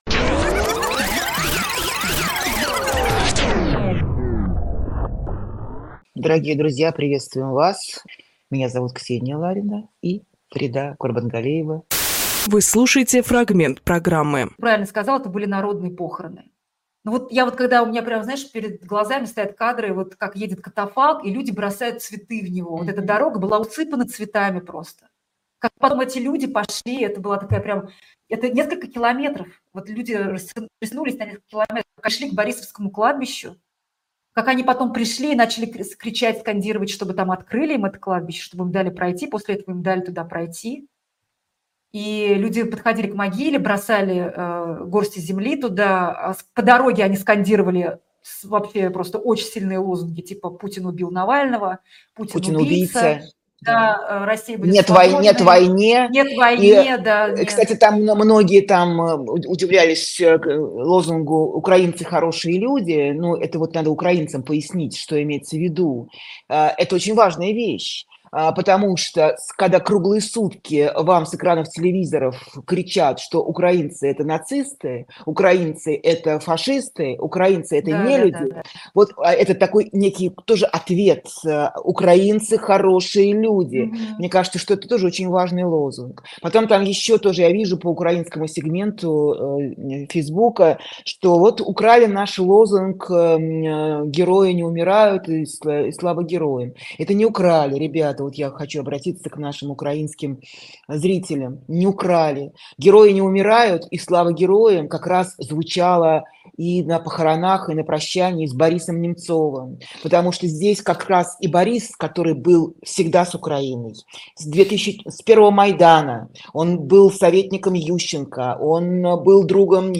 Фрагмент эфира от 2 марта.